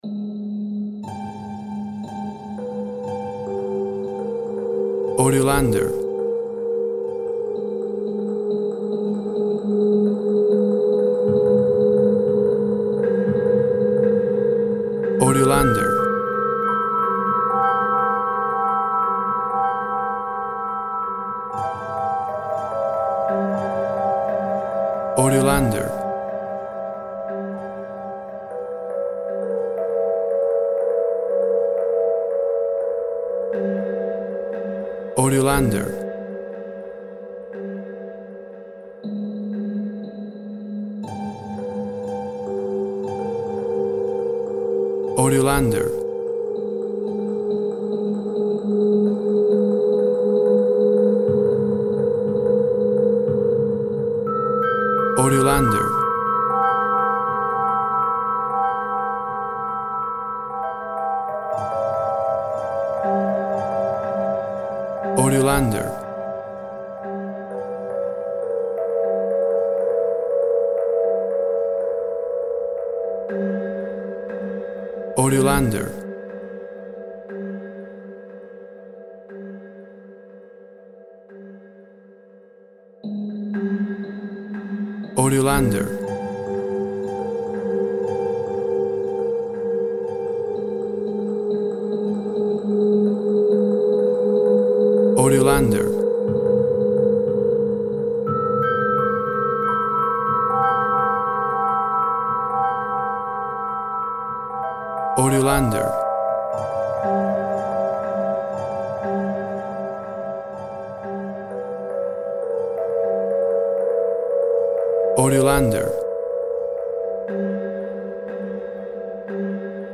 Full of scary haunting sounds!.
WAV Sample Rate 16-Bit Stereo, 44.1 kHz